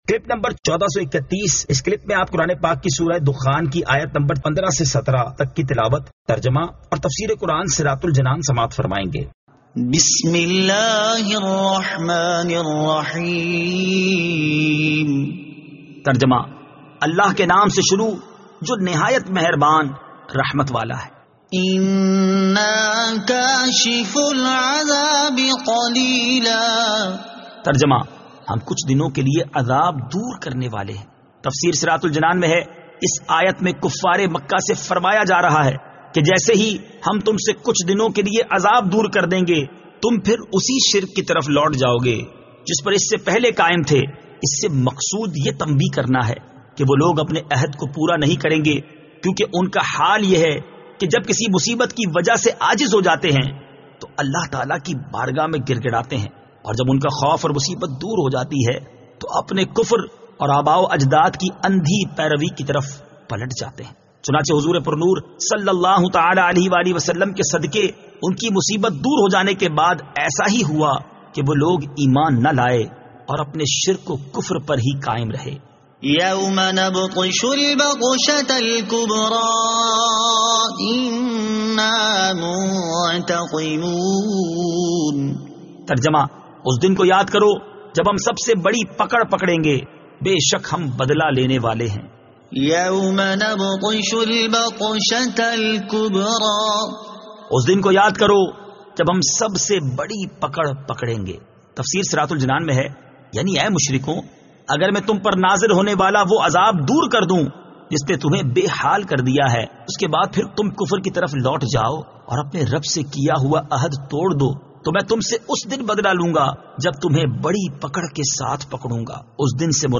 Surah Ad-Dukhan 15 To 17 Tilawat , Tarjama , Tafseer